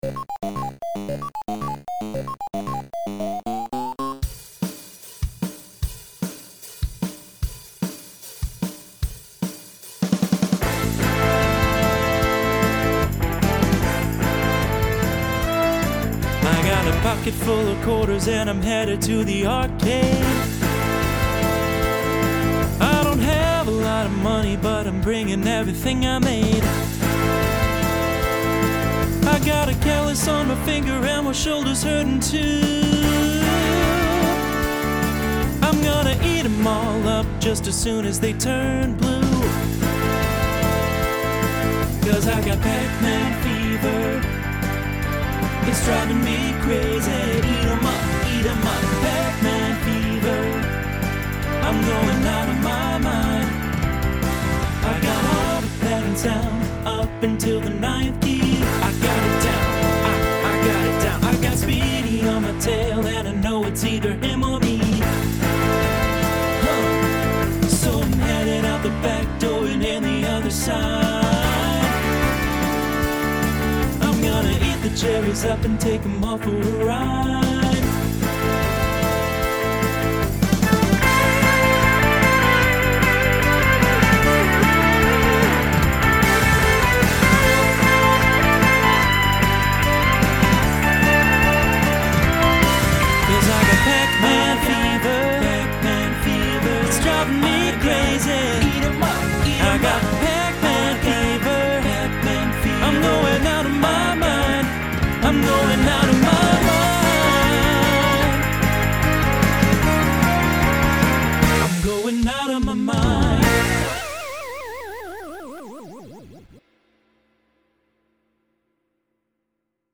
Genre Rock Instrumental combo
Transition Voicing TTB